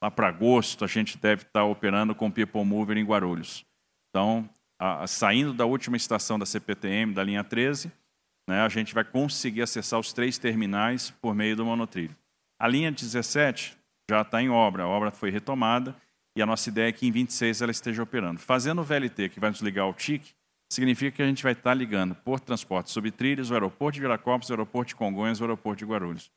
Na noite desta quinta-feira, 23 de maio de 2024, durante a reunião que faz parte do Programa de Parcerias de Investimentos do Estado (PPI), o governador de São Paulo, Tarcísio de Freitas, forneceu novas atualizações referente ao aeromóvel do Aeroporto Internacional de Guarulhos.